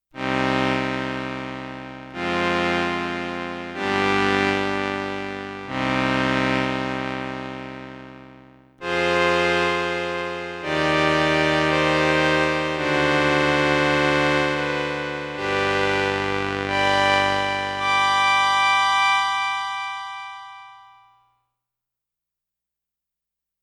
The Roland RS-202 is a classic 1970s "string ensemble" keyboard, with a sound and and an ensemble effect similar to the classic Solina.
04 BRASS with slow attack & sustain
04 BRASS with slow attack & sustain.mp3